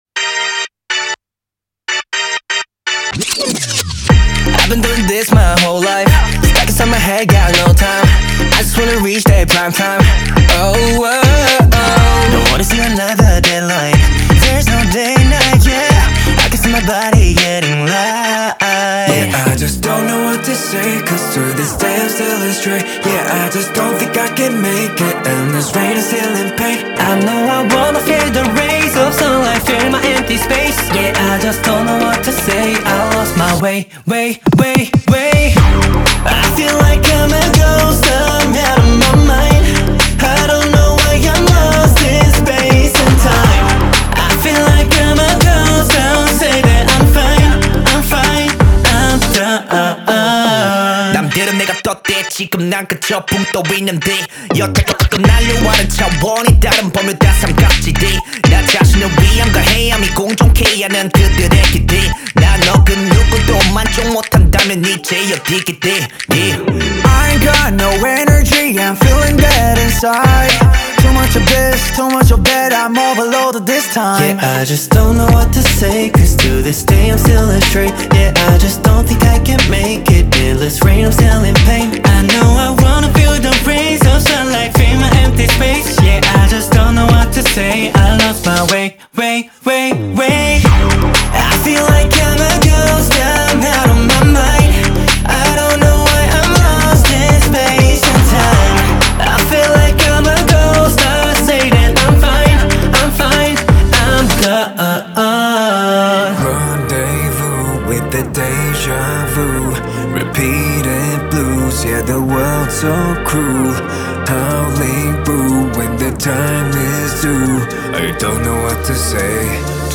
- Заключение: Песня заканчивается на оптимистичной ноте.